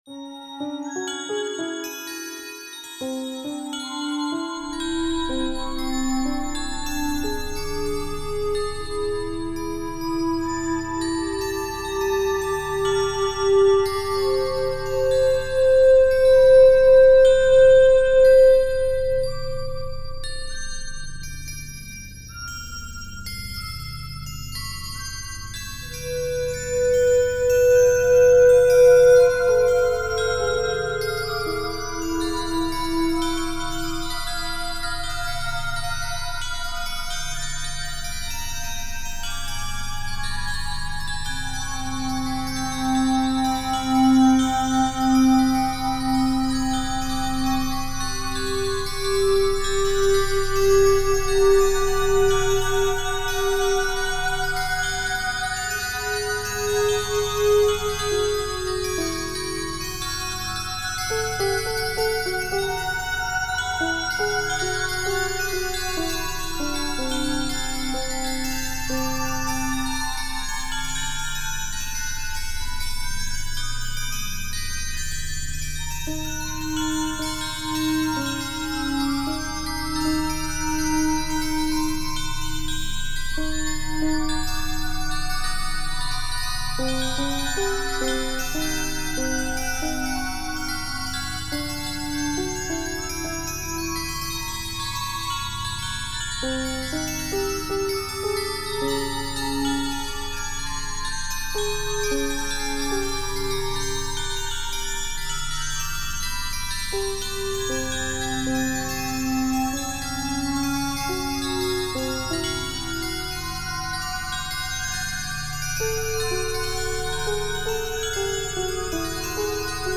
The installation comprises a automatic weather station connected, via a computer, to an electronic musical instrument.
Thus, when the wind blows, phrases are generated whose pitch, intensity and statistical density reflect wind speed and direction; other notes change with the rise and fall of temperature or pressure; and random percussive events occur with changes in rainfall.